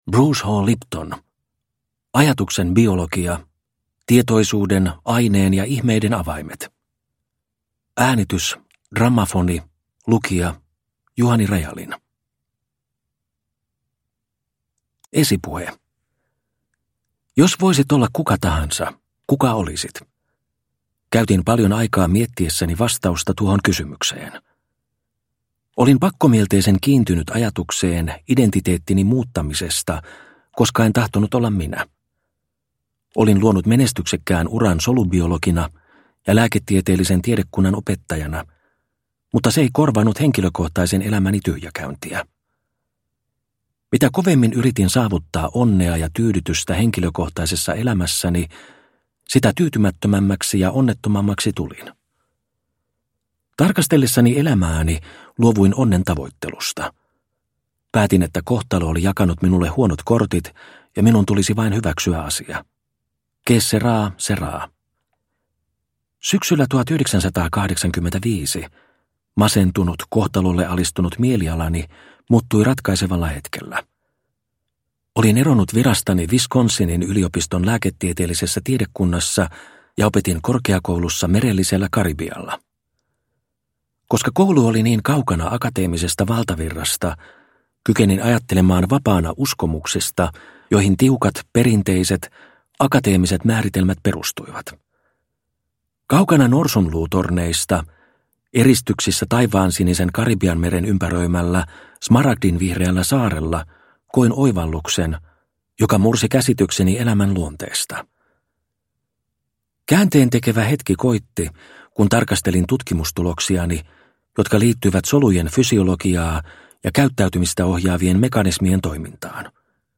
Ajatuksen biologia – Ljudbok – Laddas ner